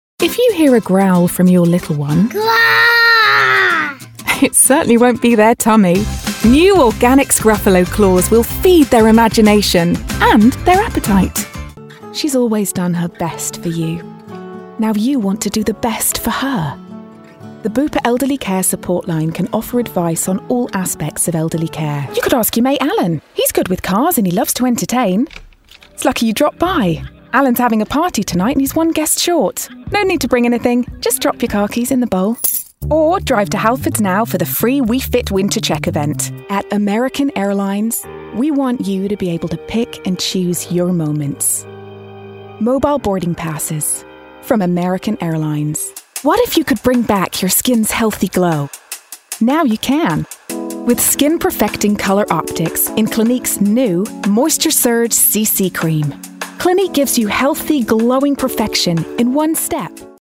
Commercial
20s-40s - Soft, versatile, engaging
Standard English/RP, American, London/Cockney, Mid-Atlantic, Northern (English), Irish, Liverpudlian
Actors/Actresses, Corporate/Informative, Natural/Fresh, Smooth/Soft-Sell, Understated/Low Key, Character/Animation, Upbeat/Energy